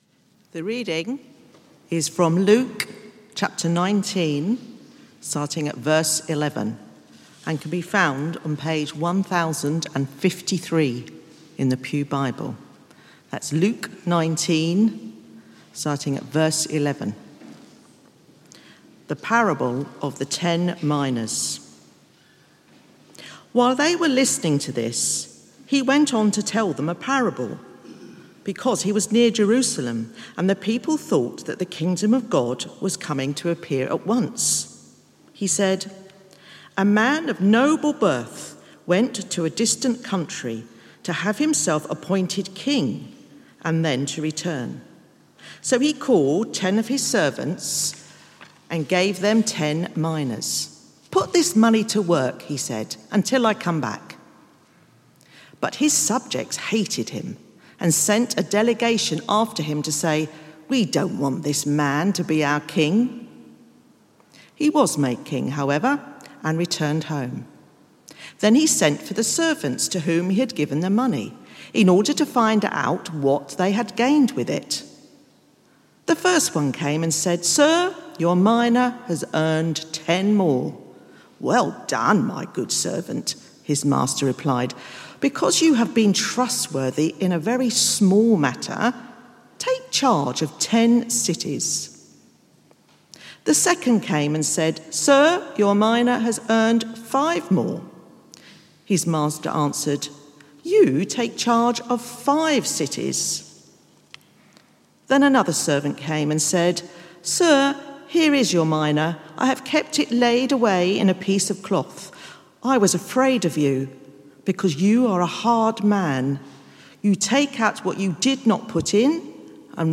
From Service: "6:00 pm Service"